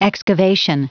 Prononciation du mot excavation en anglais (fichier audio)
Prononciation du mot : excavation